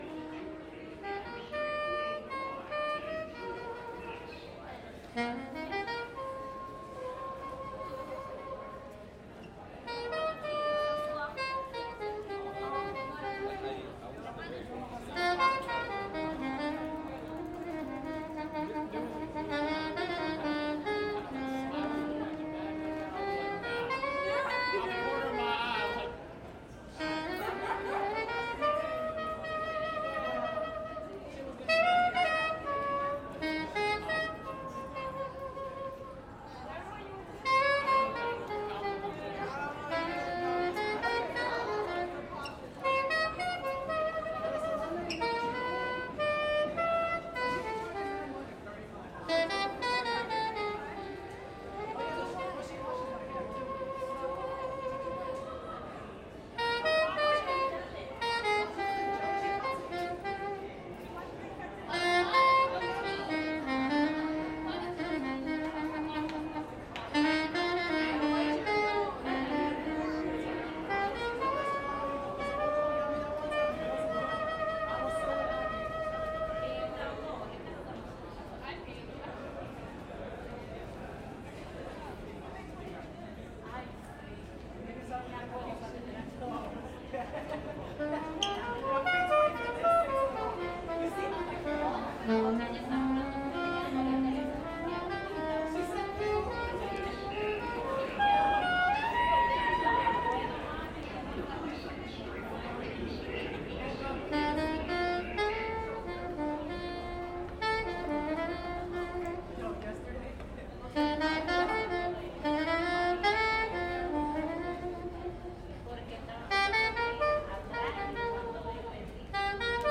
Sax métro NYC
subway-sax.mp3